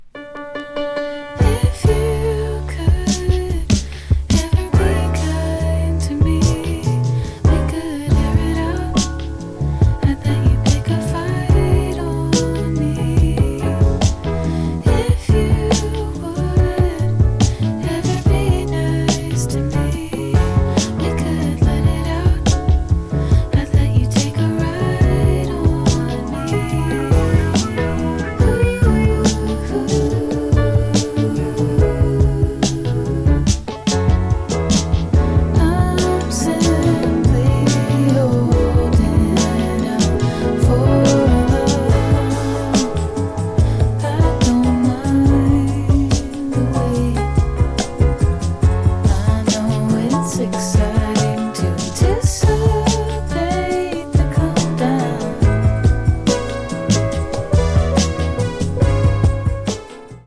New Release Soul / Funk